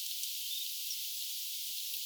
mikä tiainen?